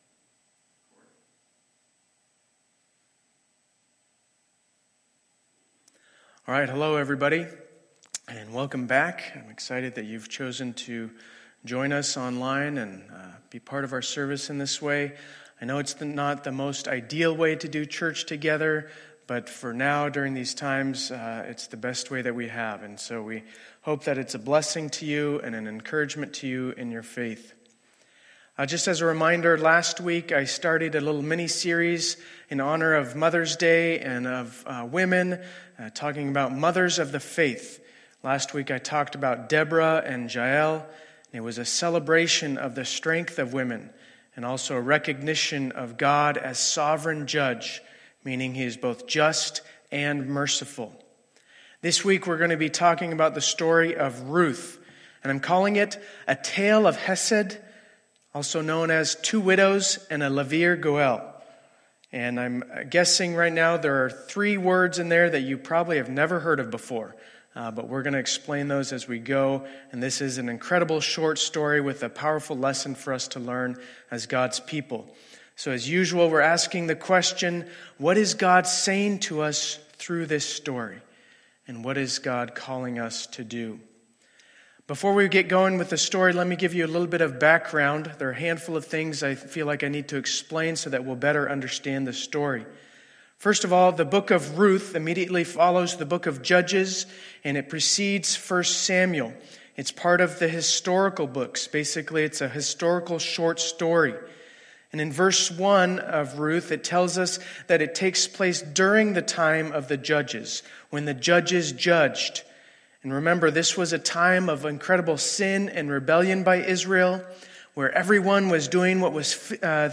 2020-05-17 Sunday Service
Sermon Audio File